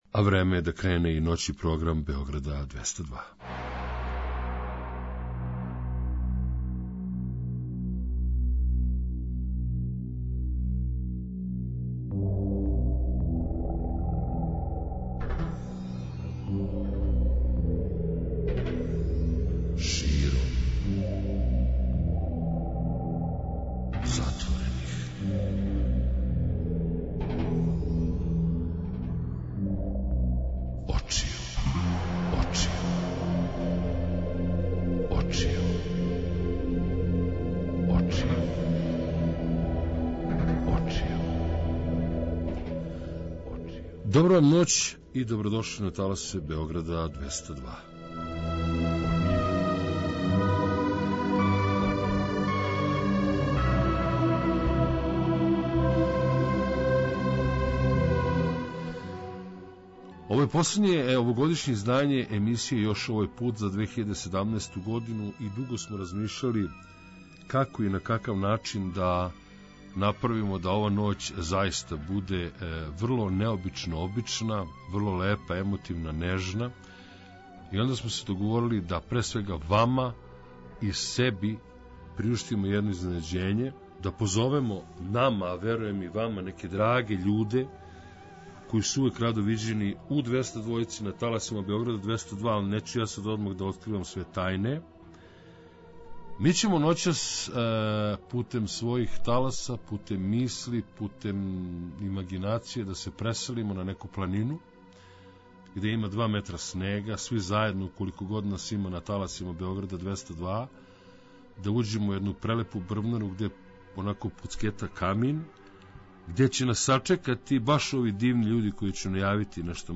У последњем овогодишњем издању емисија ЈОП направиће једну фину, лагану журку на таласима Београда 202 заједно са групом Рок Булевар.